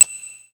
Ting.aif